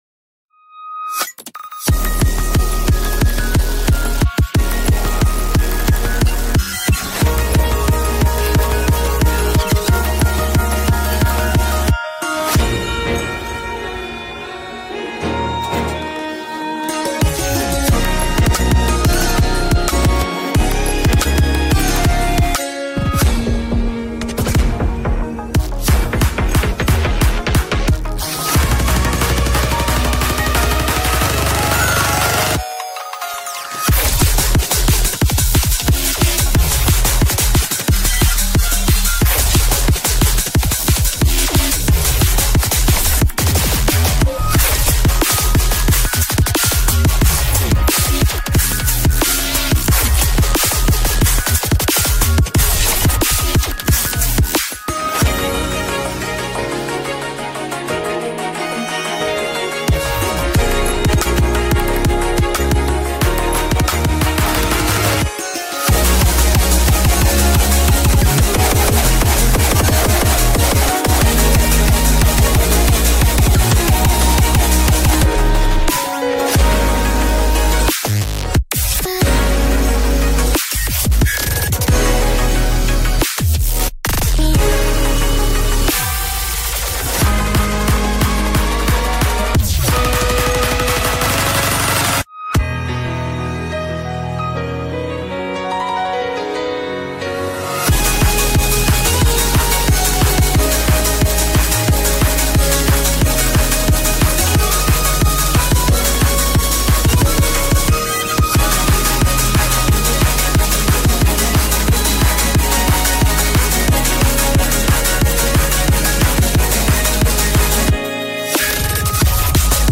BPM90-180
Audio QualityPerfect (Low Quality)